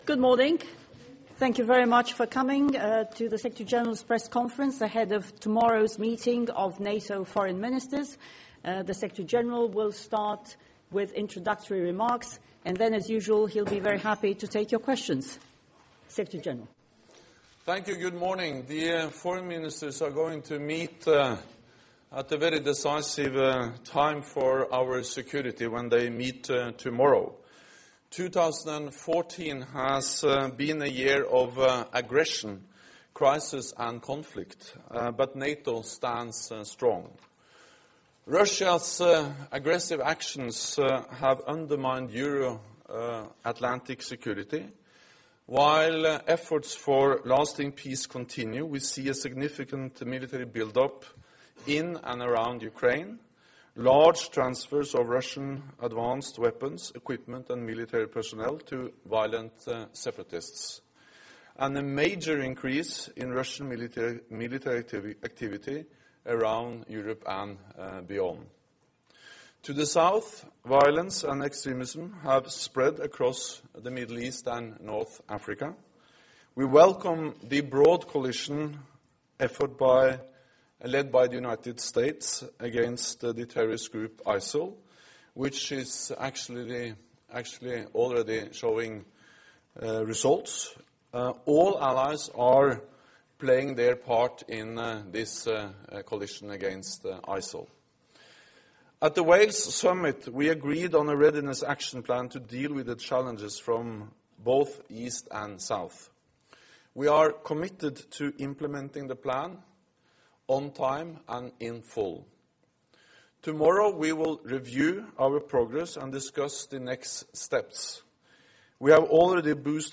Pre-ministerial press conference by NATO Secretary General Jens Stoltenberg